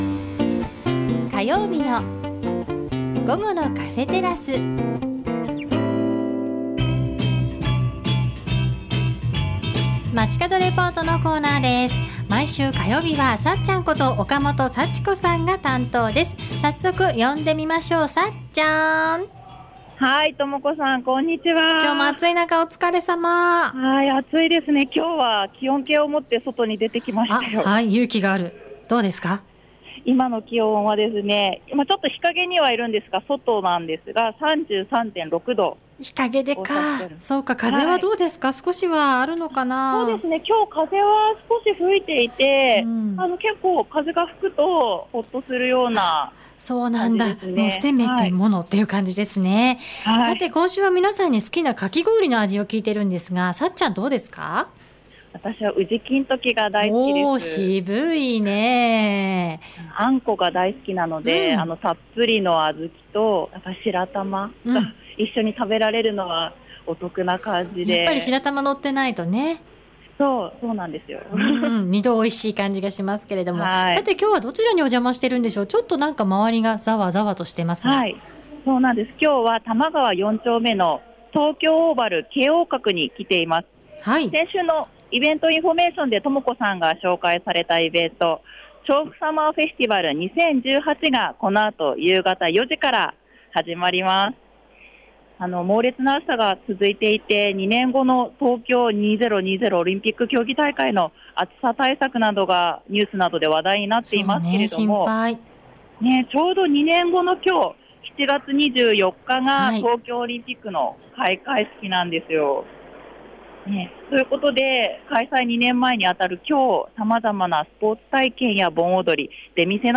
準備中の会場にお邪魔しました。